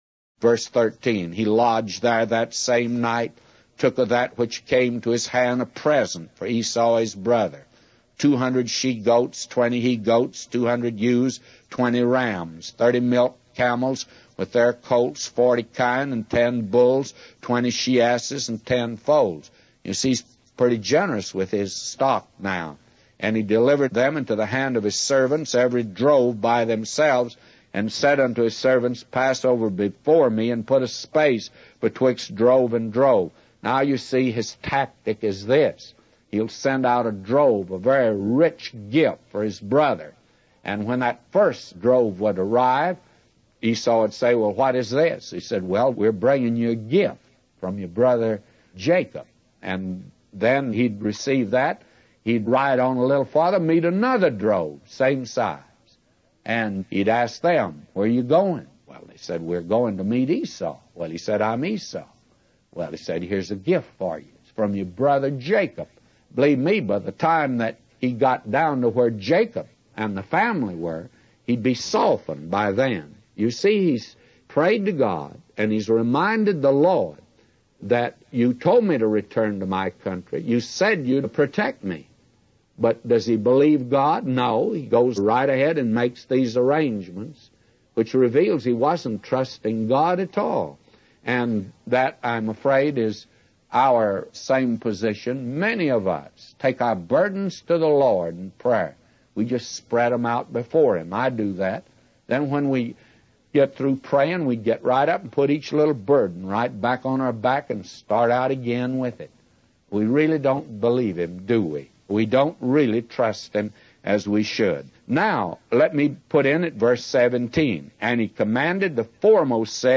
In this sermon, the preacher discusses the story of Jacob and his preparations to meet his brother Esau.